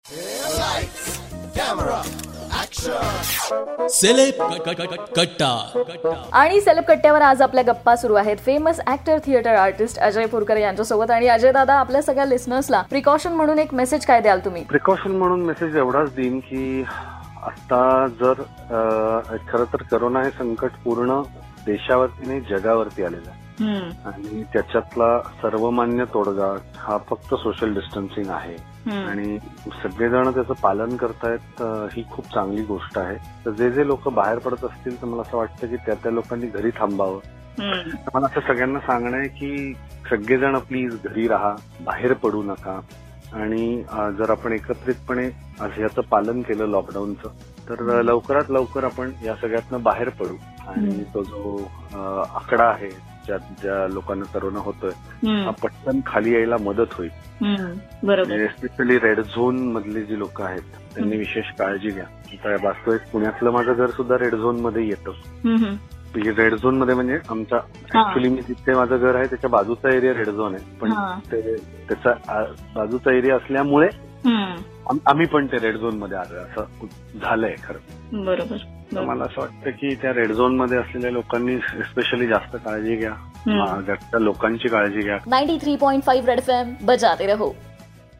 In this interview he gave some tips about what kind of precautions everyone needs to take in this situation..